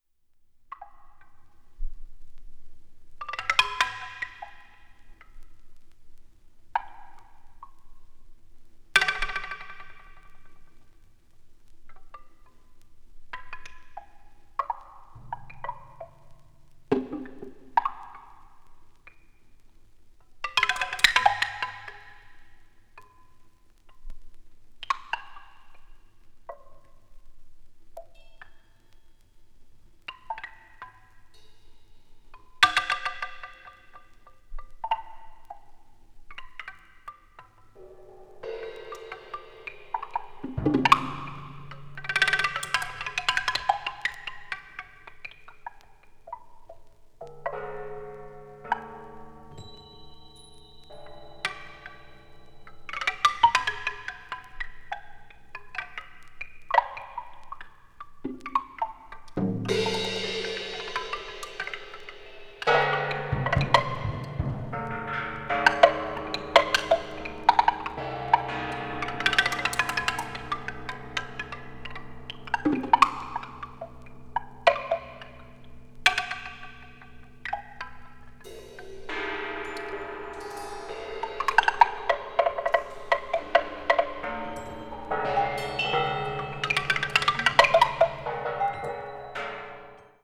media : EX/EX(わずかにチリノイズが入る箇所あり)
20th century   contemporary   percussion solo   post modern